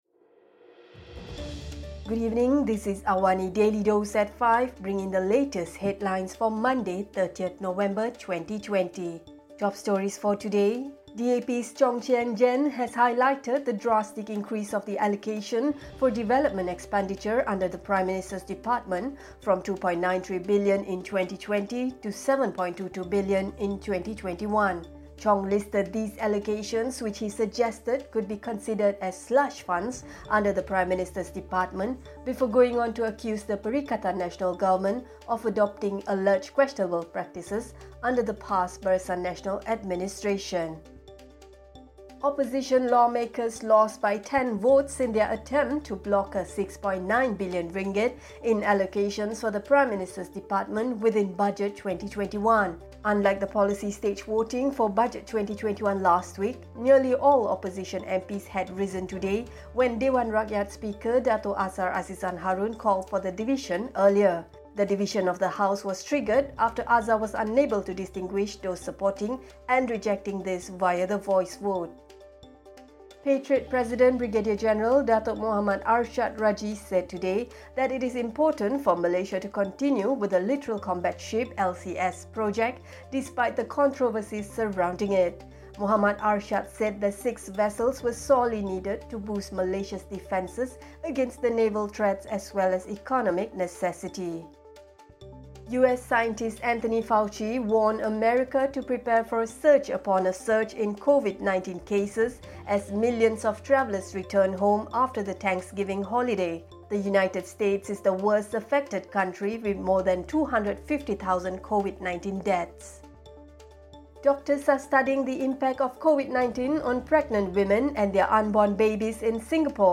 Also, Singapore doctors are studying the impact of coronavirus on unborn babies to understand whether the infection can be transferred during pregnancy, how babies develop antibodies in the womb and whether they offer an effective shield against the virus. Listen to the top stories of the day, reporting from Astro AWANI newsroom — all in 3 minutes.